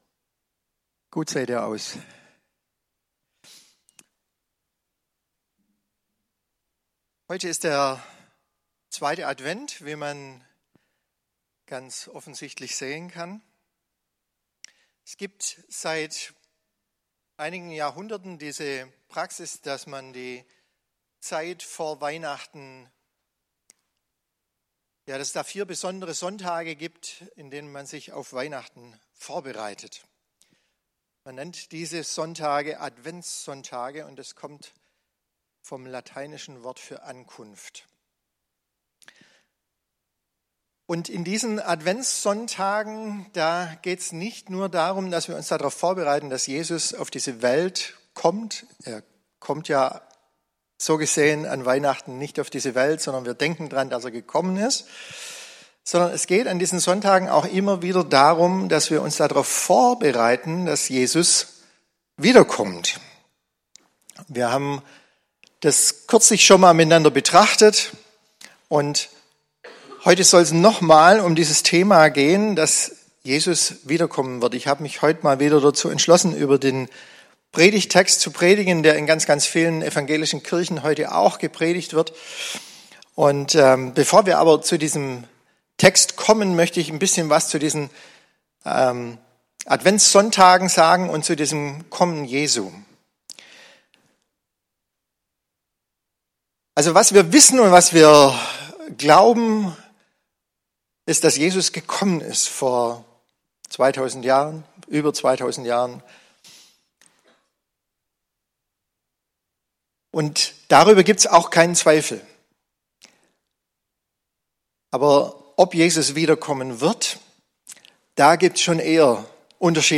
Predigt-Details - FCG Ecclesia Laupheim